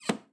ClosetCloseImpact.wav